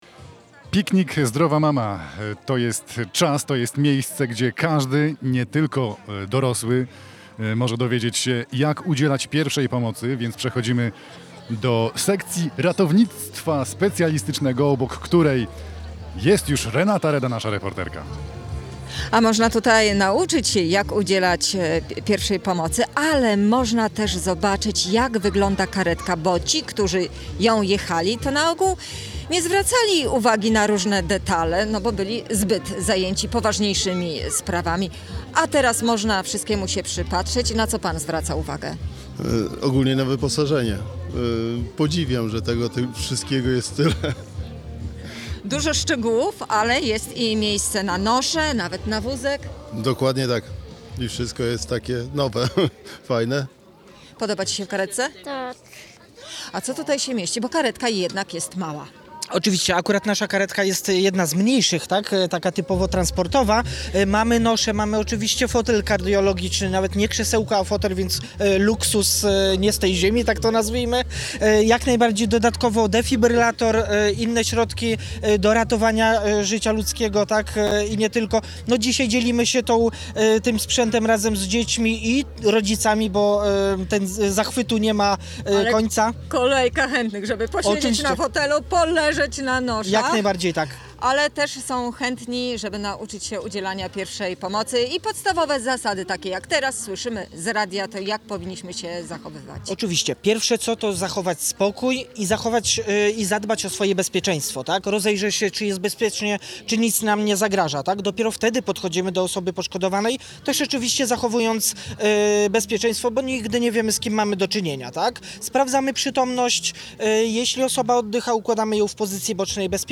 odwiedza sekcję ratownictwa medycznego